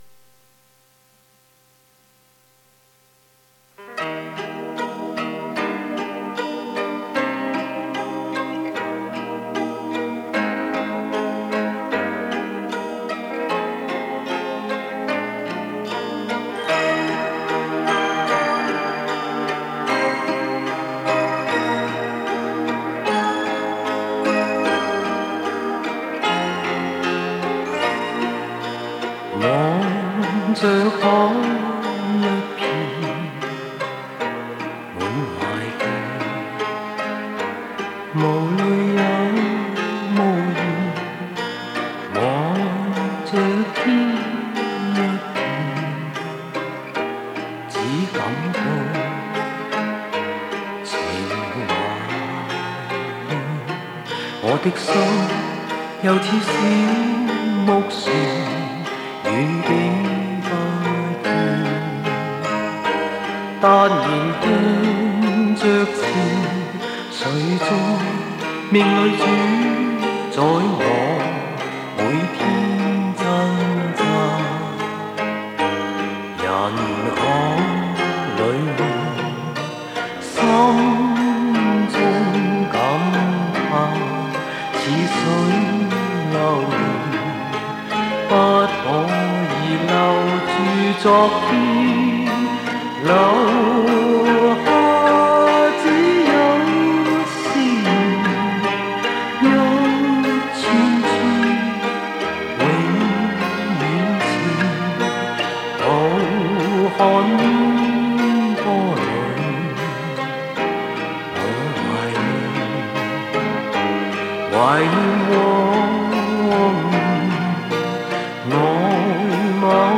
磁带数字化：2022-07-03